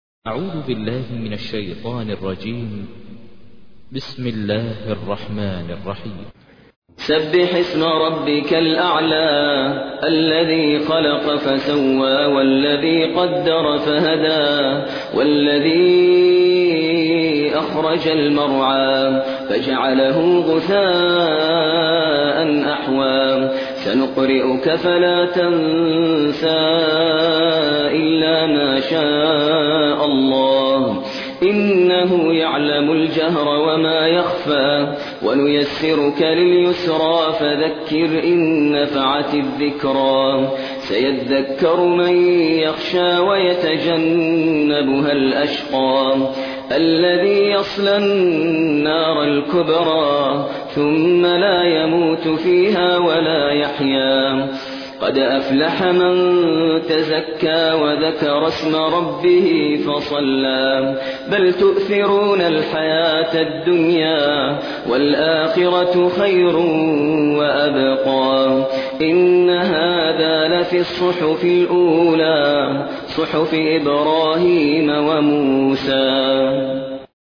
تحميل : 87. سورة الأعلى / القارئ ماهر المعيقلي / القرآن الكريم / موقع يا حسين